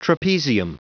Prononciation du mot trapezium en anglais (fichier audio)
Prononciation du mot : trapezium